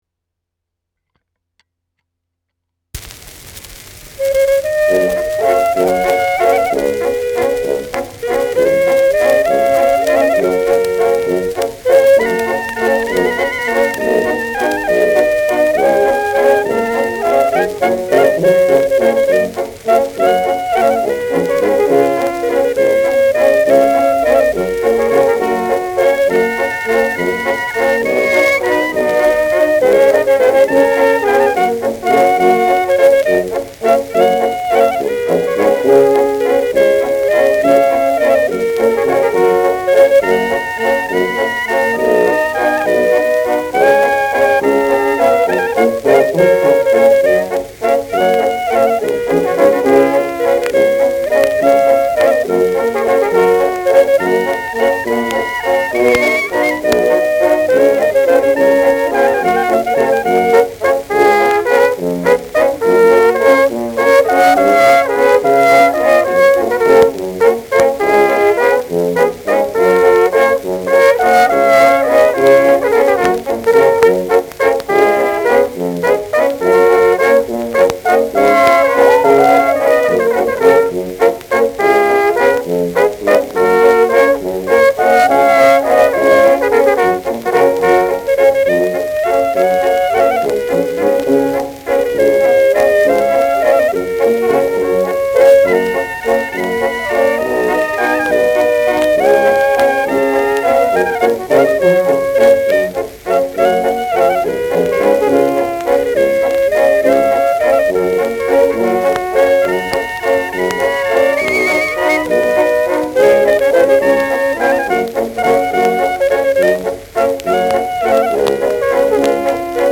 Schellackplatte
präsentes Knistern : leiert : leichtes Rauschen : abgespielt : Knacken gegen Ende
Mit Juchzern.